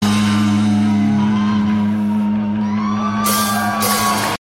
Category: AOR
guitar and vocals
bass and vocals
drums and vocals